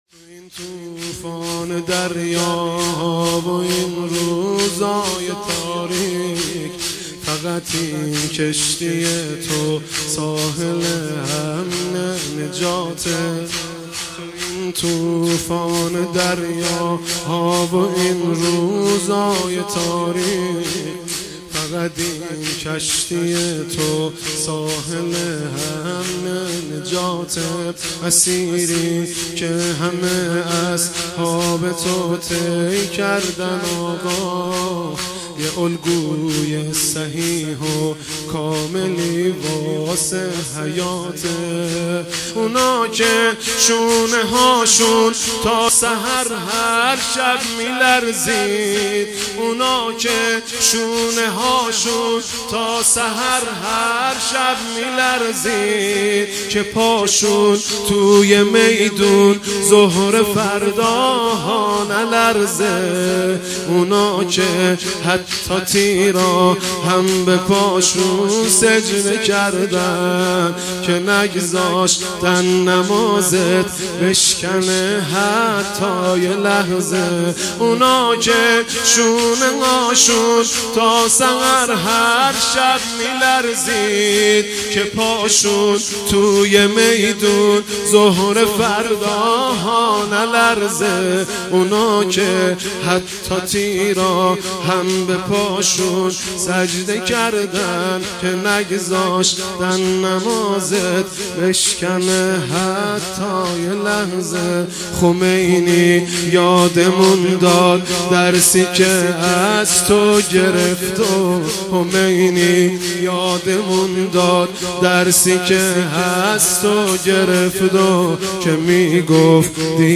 شور